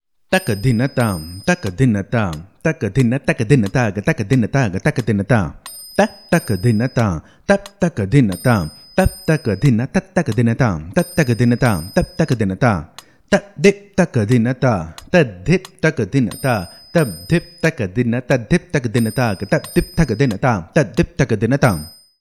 This is a mukthayam of 32 beats, which is a combination of both chaturashra nade and trishra nade.
Konnakol